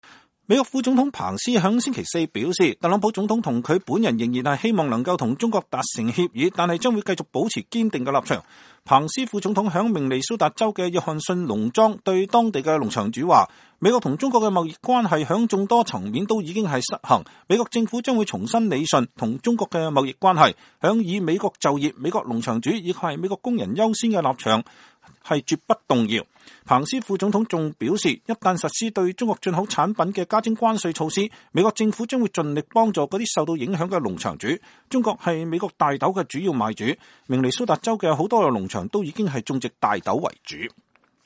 彭斯副總統在明尼蘇達州的約翰遜農莊對當地的農場主說，美國與中國的貿易關係在眾多層面都已失衡，美國政府將重新理順與中國的貿易關係，在以美國就業、美國農場主和美國工人優先的立場長絕不動搖。